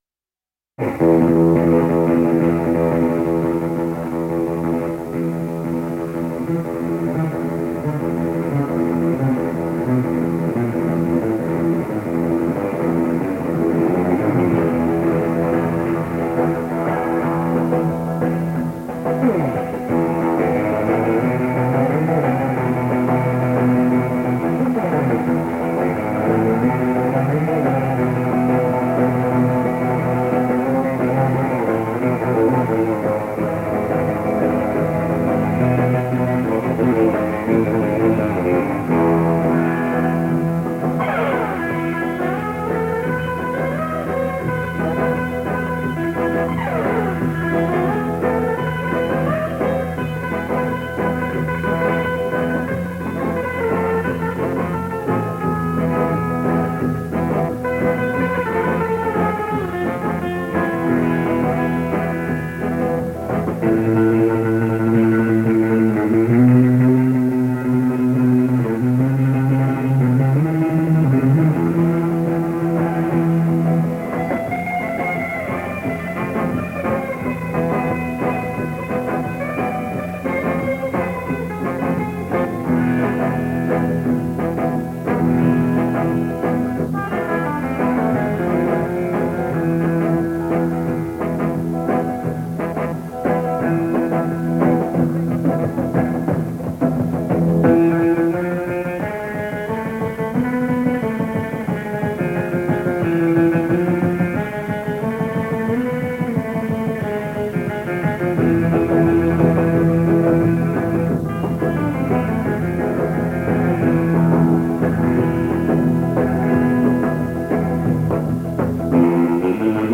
Soundtrack, Rock, Soul